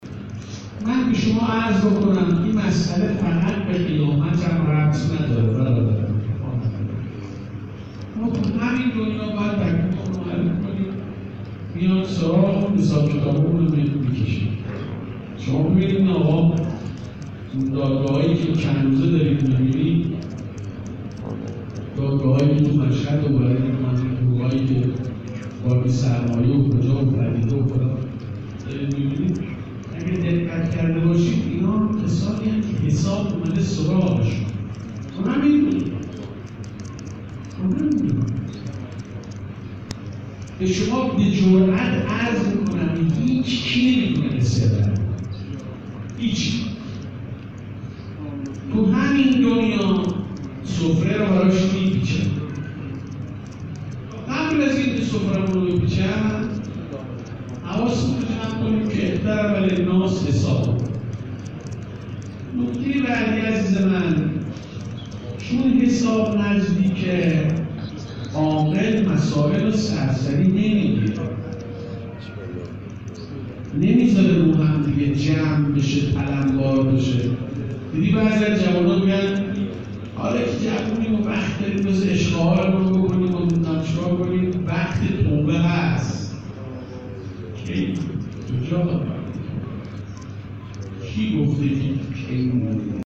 سحرگاه دوشنبه در امامزاده شاهزید اصفهان